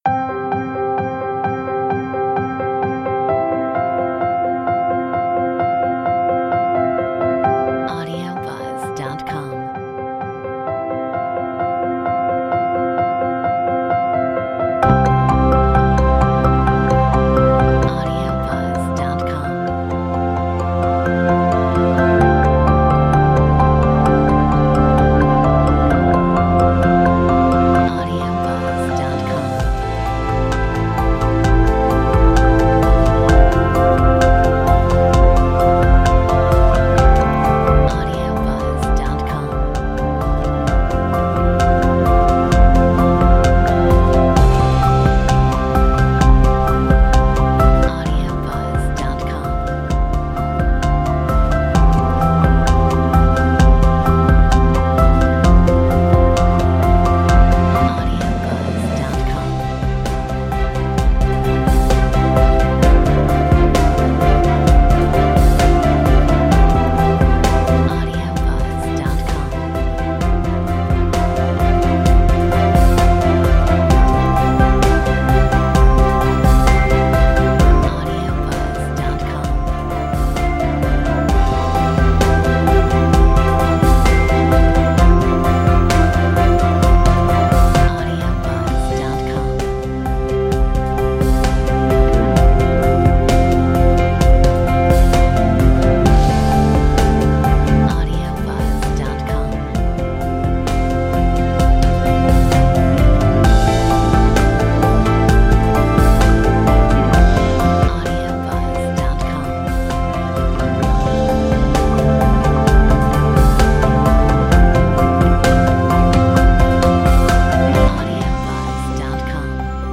Metronome 65